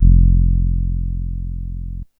808s
F_07_Bass_02_SP.wav